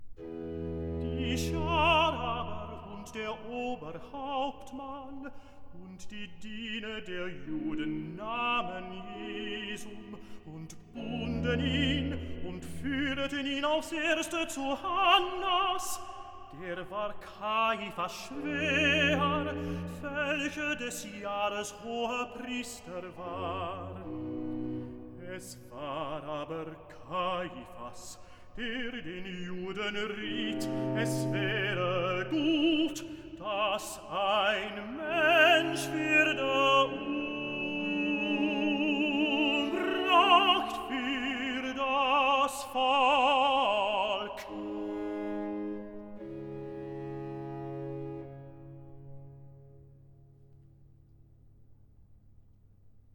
Evangelist